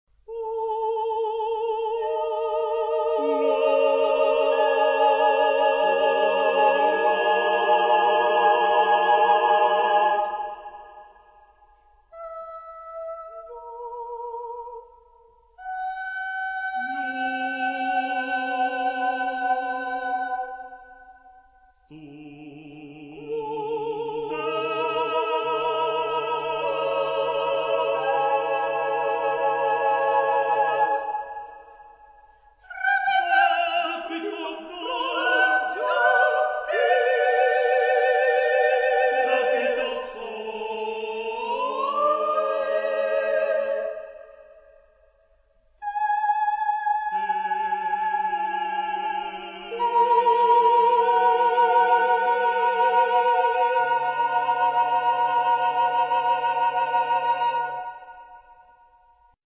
Epoque: 20th century
Type of Choir: SSATB  (5 mixed voices )